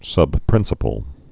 (sŭb-prĭnsə-pəl)